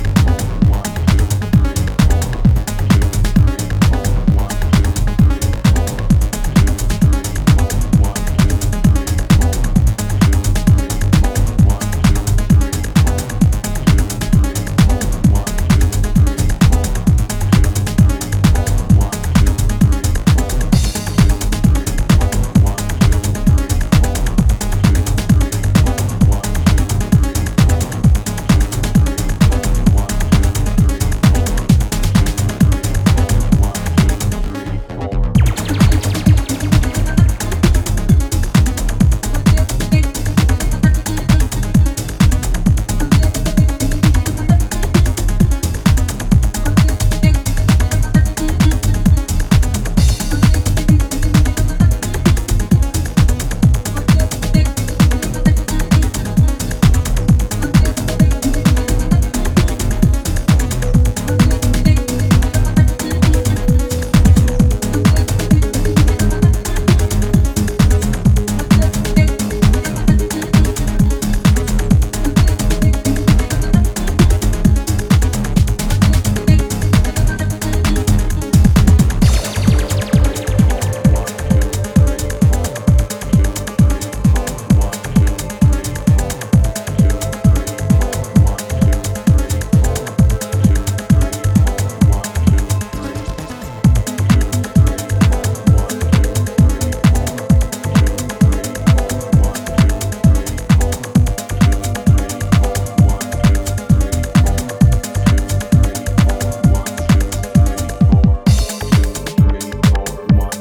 Hypnotic and with a characteristic acid strength